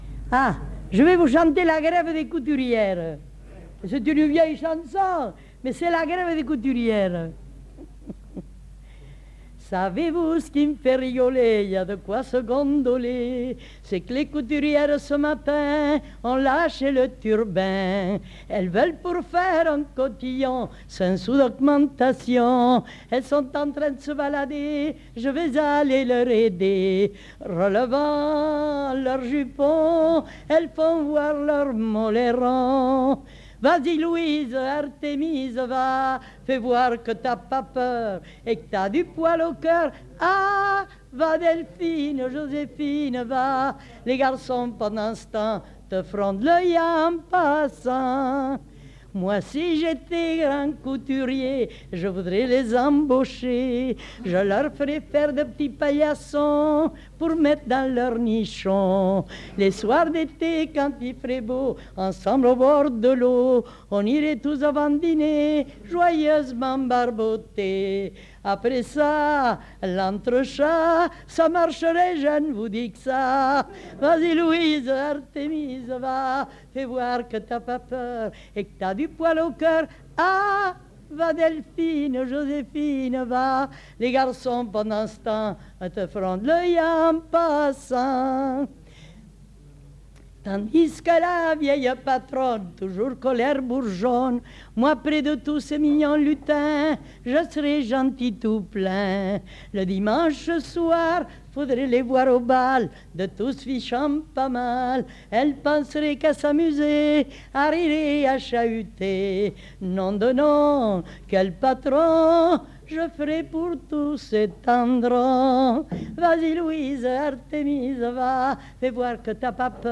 Lieu : Orbe
Genre : chant
Effectif : 1
Type de voix : voix de femme
Production du son : chanté